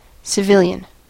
En-us-civilian.mp3